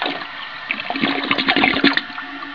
toilet.wav